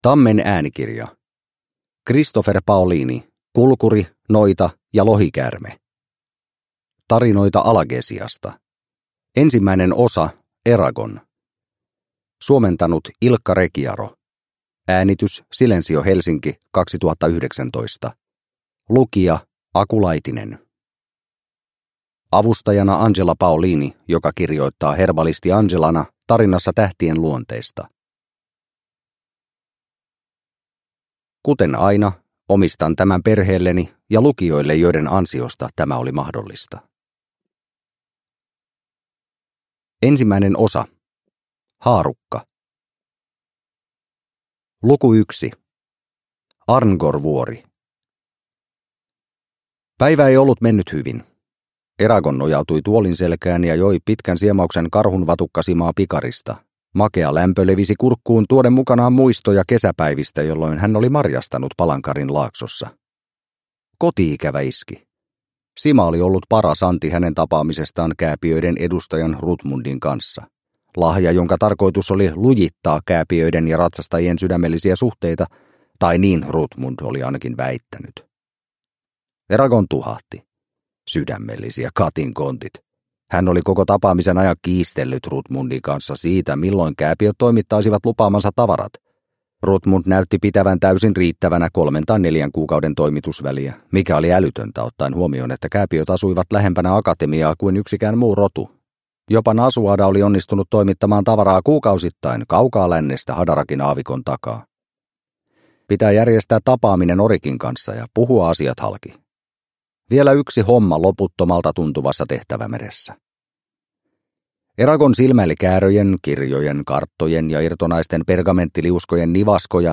Kulkuri, noita ja lohikäärme (ljudbok) av Christopher Paolini